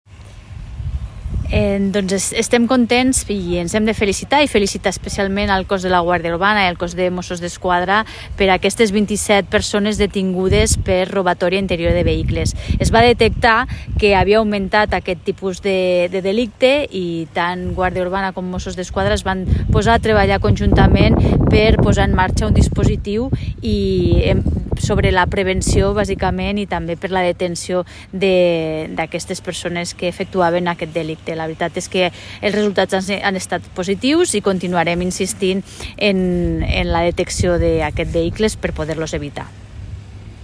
Tall de veu de la 3a tinenta d'alcalde i regidora de Seguretat, Mobilitat i Civisme, Cristina Morón, sobre les detencions per robatoris en interior de vehicles